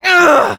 Voice file from Team Fortress 2 French version.
Scout_painsharp02_fr.wav